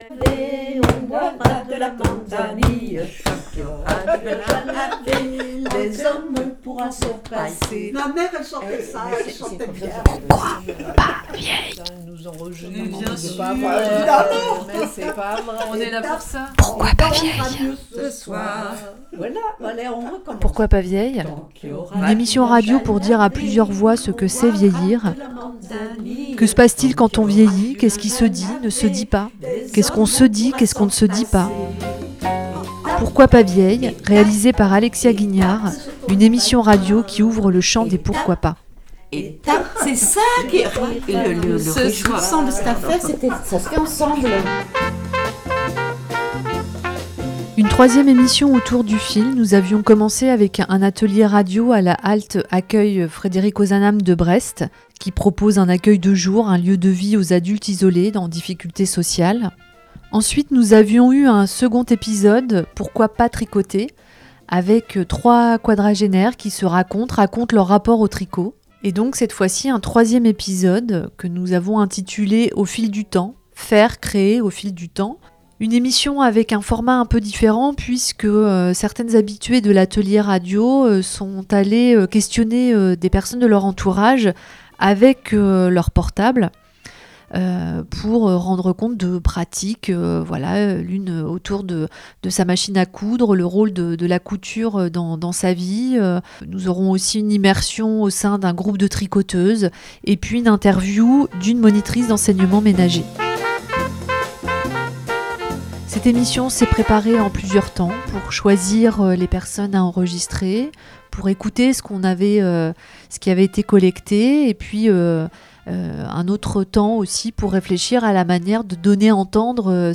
De fil en fil, cet épisode de Pourquoi pas vieille donne la parole à des femmes de différents âges, des couturières, des tricoteuses...De parole en parole, les fils de nos histoires se déroulent, se mêlent et se nouent pour tisser un récit collectif.
Ensuite trois quadragénaires nous ont raconté leurs histoires de fil autour du tricot.
Trois d'entre elles sont allées collecter des histoires de fil, dans leur entourage, avec leur téléphone portable...